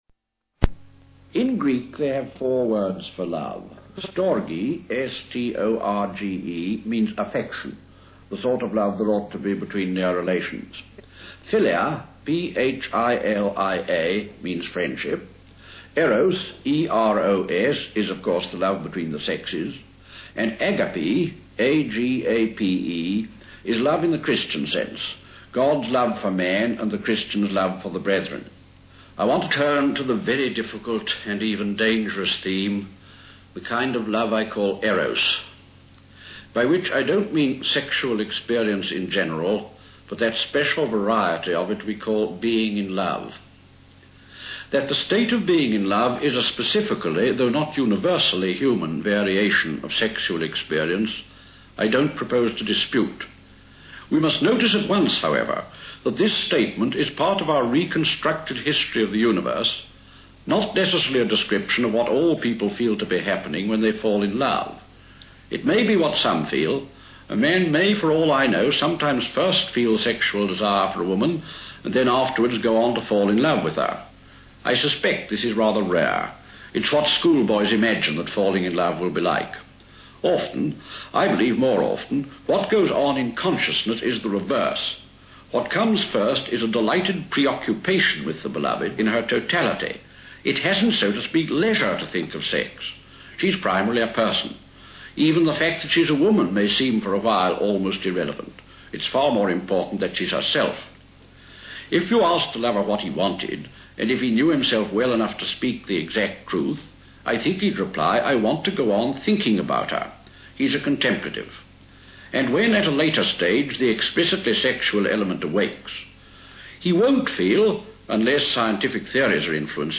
A BBC broadcast of C. S. Lewis giving a talk on Eros